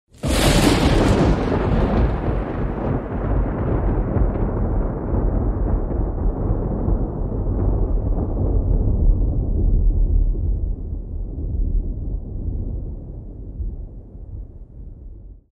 Гром для появления джина